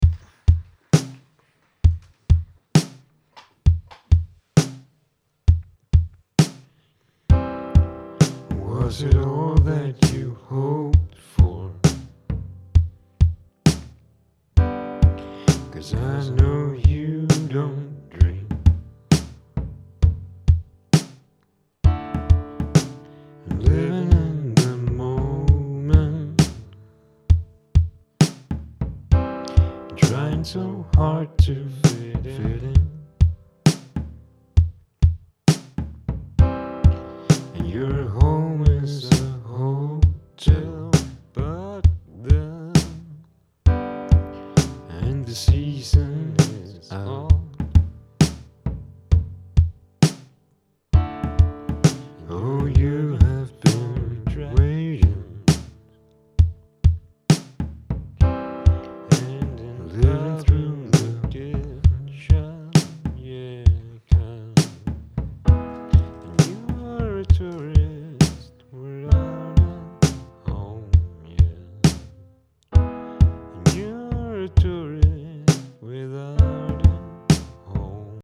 However, as I am also writing I would love to be able to sing as well but I am extremely insecure about my voice and would therefore like to hear some honest feedback regarding my singing voice. I have attached an idea I am currently working on.